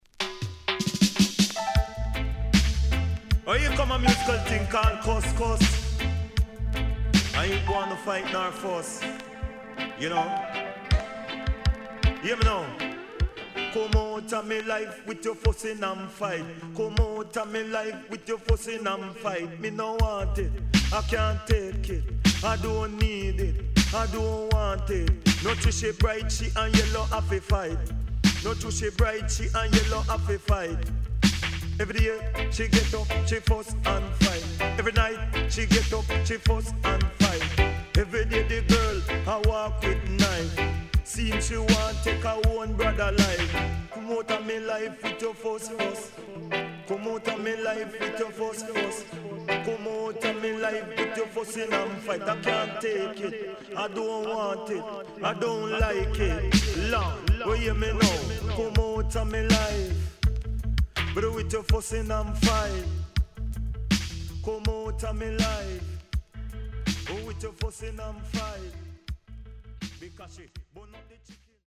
HOME > LP [DANCEHALL]
SIDE A:少しチリノイズ入ります。